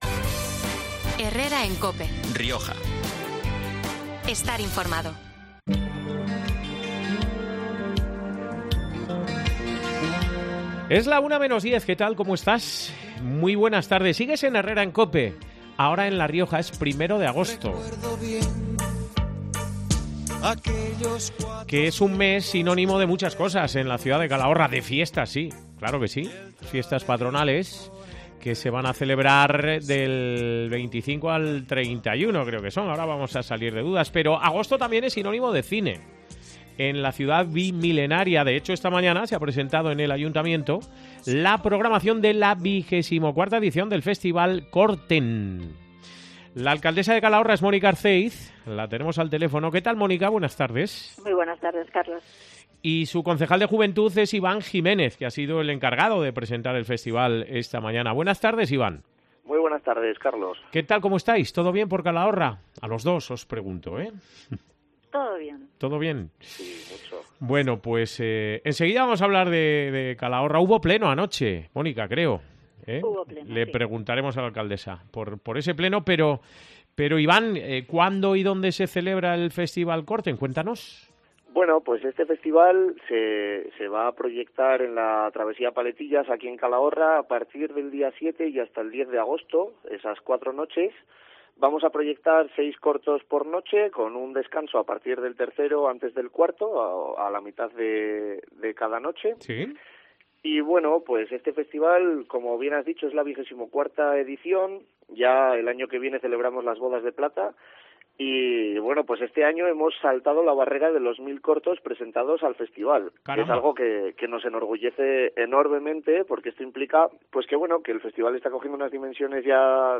Según ha detallado en COPE Rioja el concejal calagurritano de Juventud, Iván Jiménez, para esta edición se habían recibido 1.067 trabajos de los que, finalmente, se proyectarán 24.
En el espacio también ha intervenido este 1 de agosto la alcaldesa de la ciudad, Mónica Arceiz, para ofrecer un resumen del pleno celebrado anoche por la Corporación.